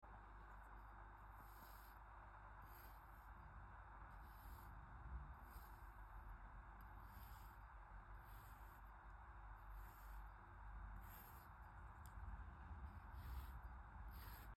ASMR Sand Scraping ⏳ X Sound Effects Free Download